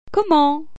Comment ?   s'uh-ee